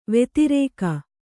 ♪ vetirēka